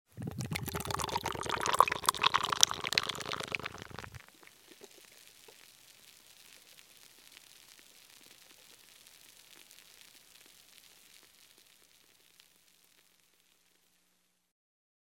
Звуки шипения пивной пены в стакане.
Наливают из банки
Наливают баночное пиво
nalivajut-iz-banki.mp3